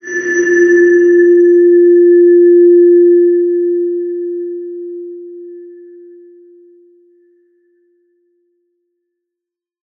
X_BasicBells-F2-pp.wav